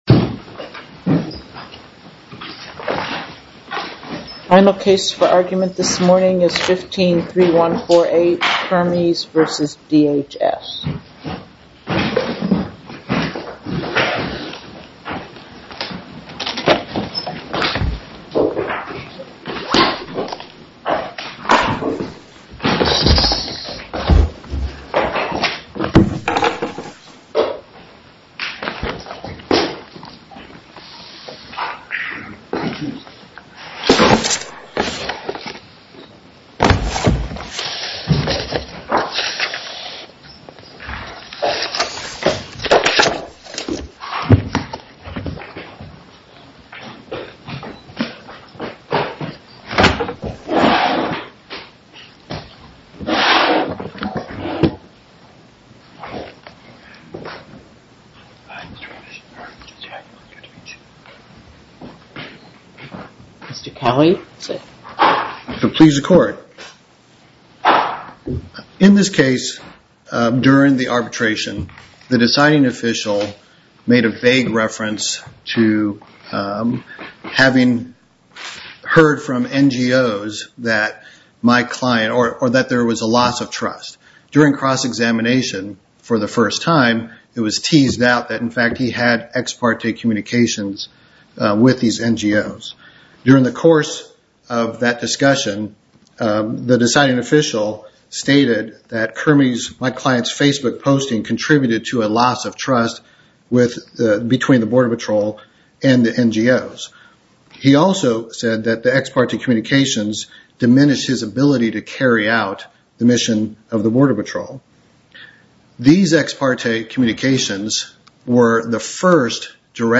Oral argument audio posted